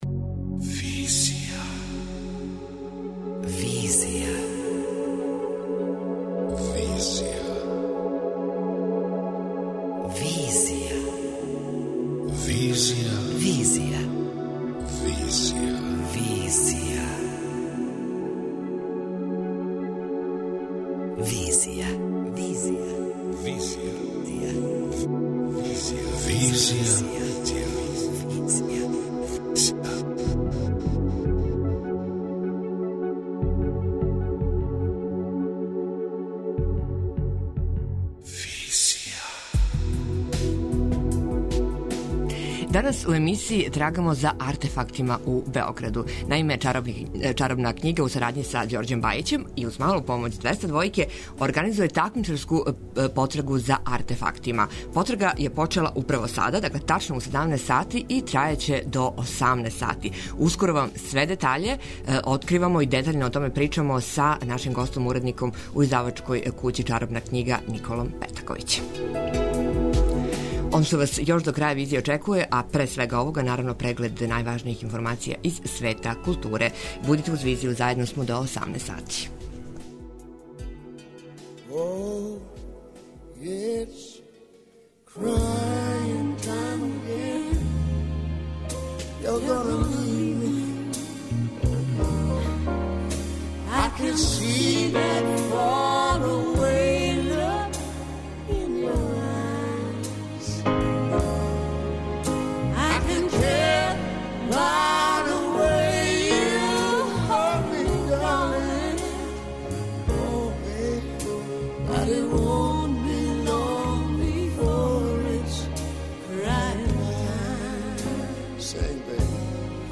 преузми : 27.22 MB Визија Autor: Београд 202 Социо-културолошки магазин, који прати савремене друштвене феномене.